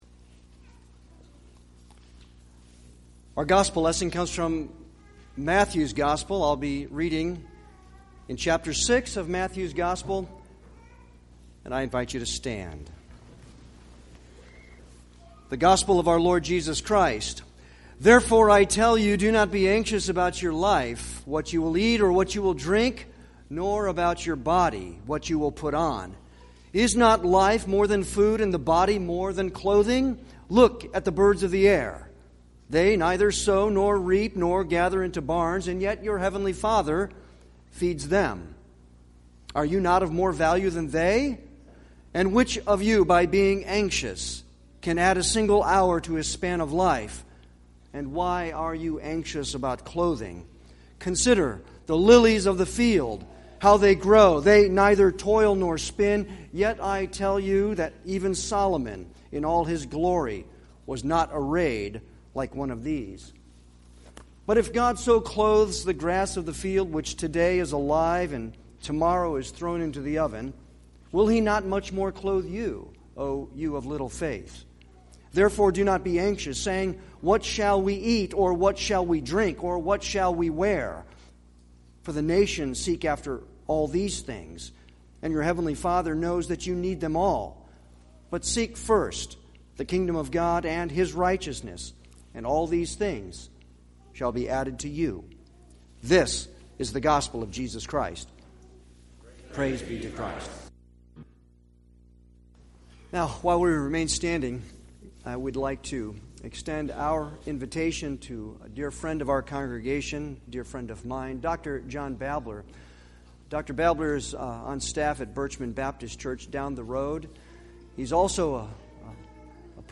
Passage: Philippians 4:4-8 Service Type: Sunday worship